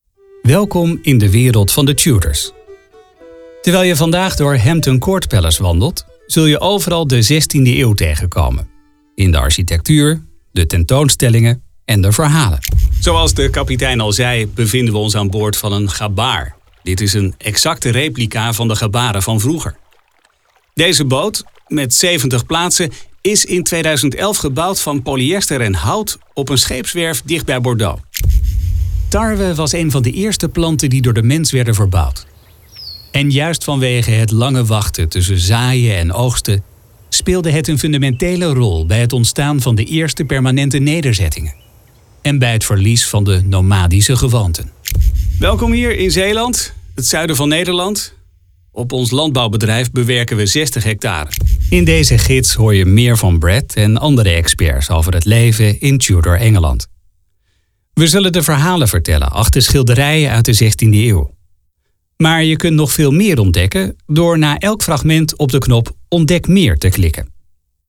Authentic Dutch voice-over with a warm tone, fast delivery and a relaxed, professional approach
Middle Aged